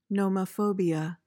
PRONUNCIATION:
(no-muh-FO-bee-uh)